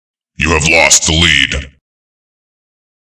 Quake 3 sounds
you-have-lost-lead.ogg